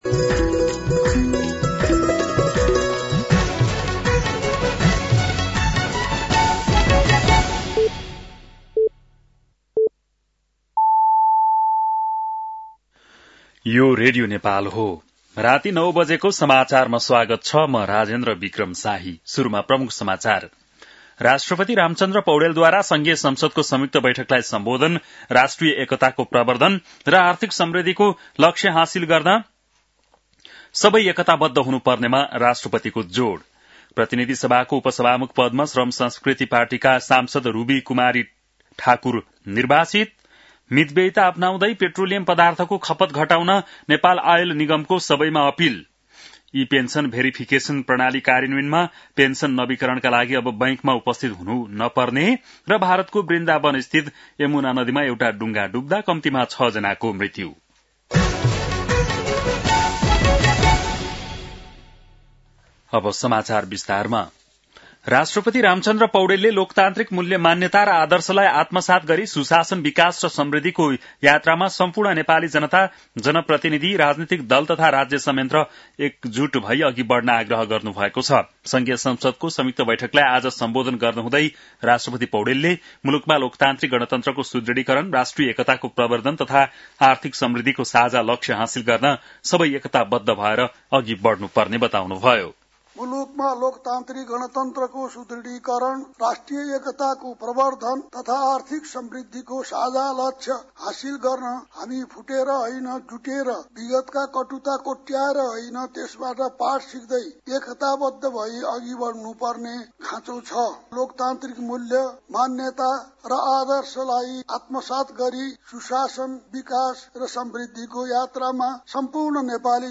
बेलुकी ९ बजेको नेपाली समाचार : २७ चैत , २०८२